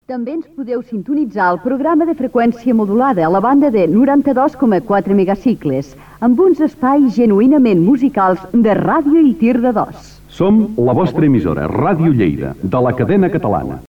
Identificació de l'emissora
FM